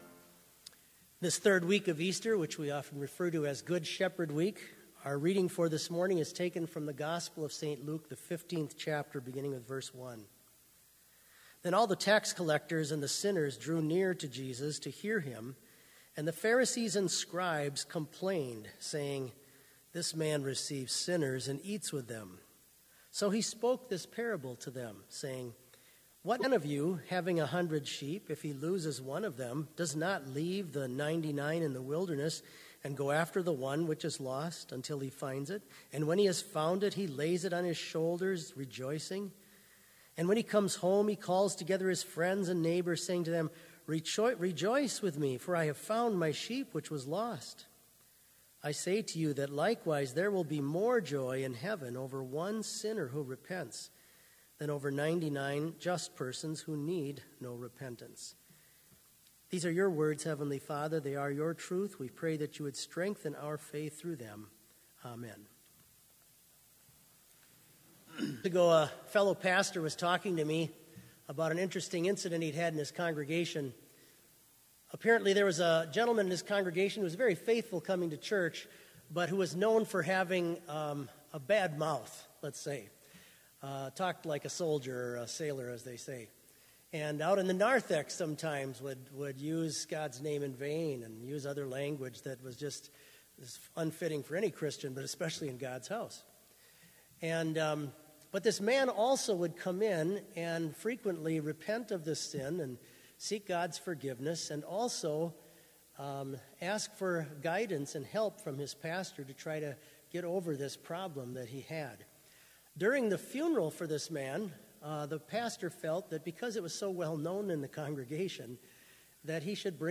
Complete service audio for Chapel - May 9, 2019